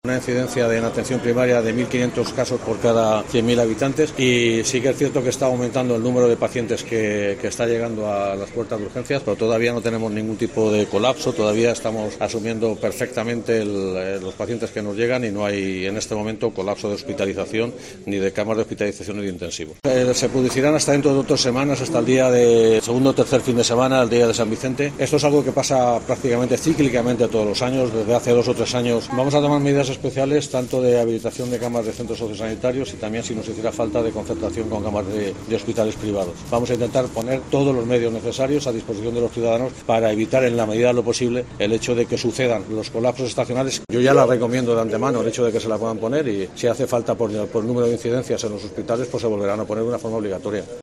Gómez ha realizado estas declaraciones antes de vacunarse frente a la covid y la gripe y al respecto ha vuelto a animar a la población a inocularse contra los virus respiratorios para "protegerse no solo a uno mismo, sino también a su entorno, a sus seres queridos, a sus compañeros de trabajo", además de evitar así un colapso del sistema sanitario.